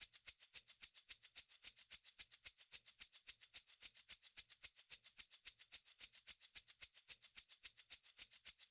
13 Shaker.wav